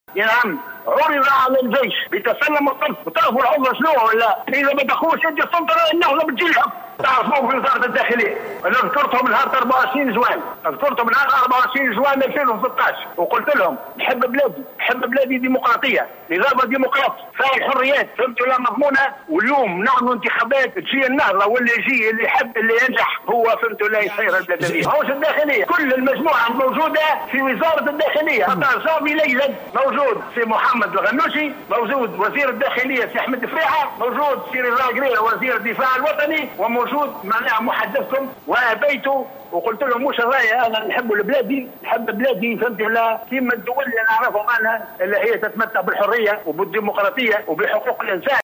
قال الجنرال السابق رشيد عمار اليوم الخميس، في مداخلة على قناة "الحوار التونسي" إن المؤسسة العسكرية عُرض عليها تسلم الحكم في البلاد يوم 14 جانفي 2011.